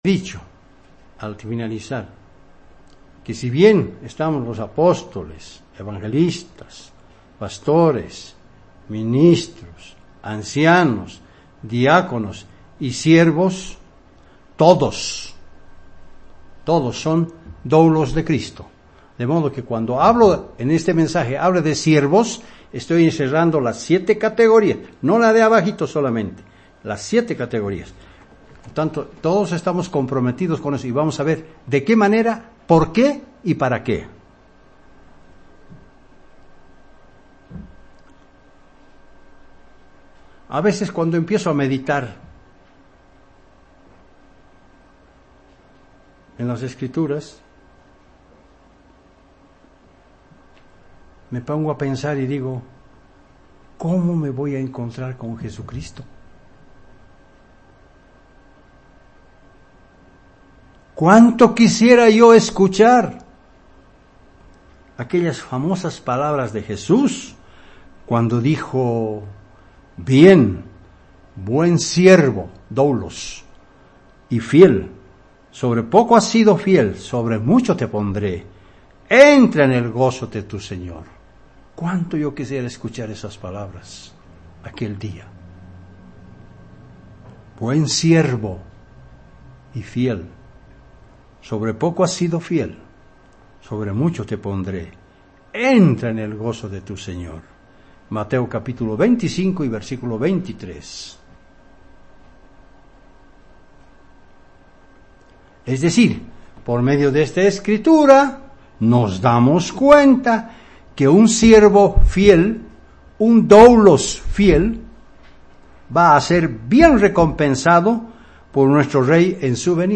El compromiso del servicio dentro de la iglesia, debe ser idéntico en todas las labores que se realizan. Mensaje entregado el 14 de Abril de 2018